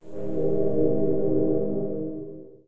YOULOSE.mp3